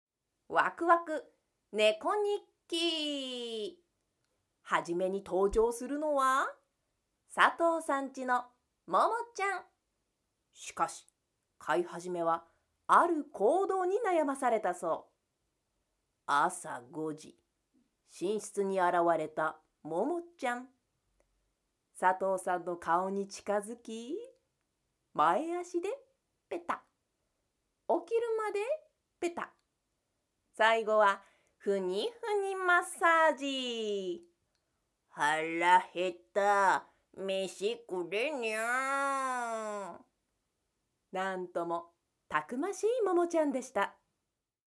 ナレーション（動物バラエティ）